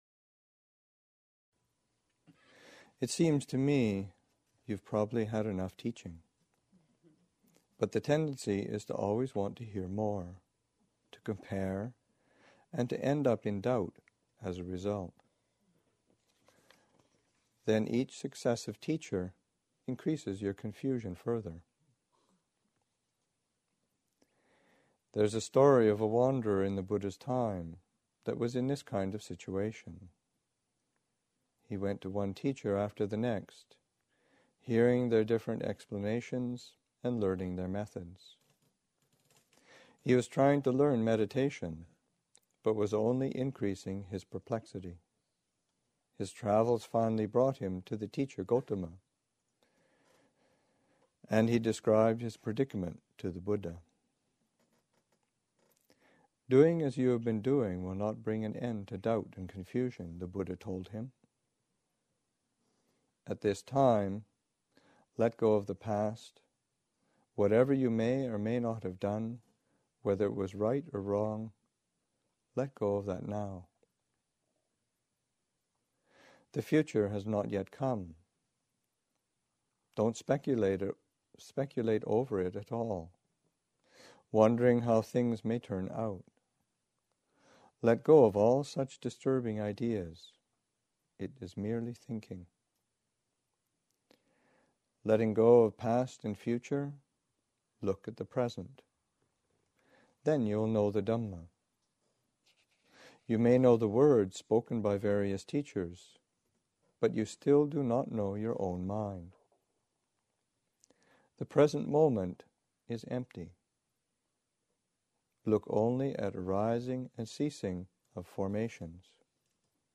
1. Reading: Beyond Doubt.